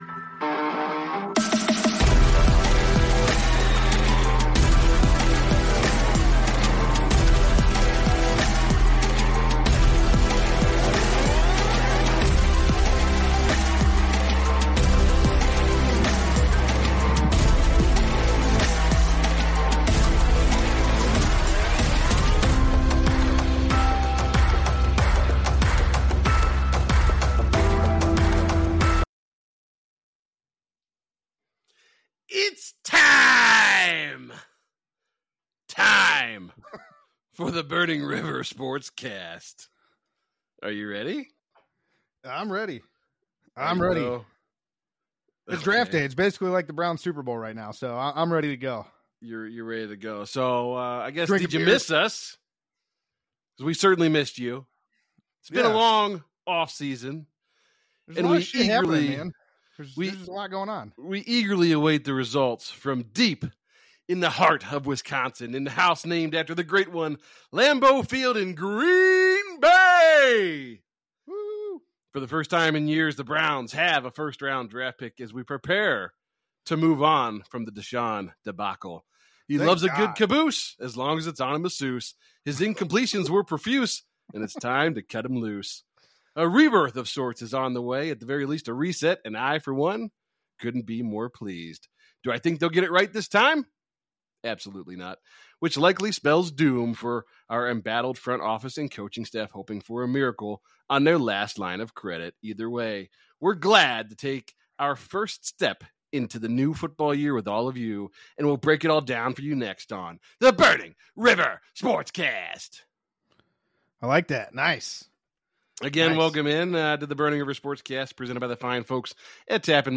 Join us for our LIVE Draft Special and follow along as the first round plays out for the Cleveland Browns!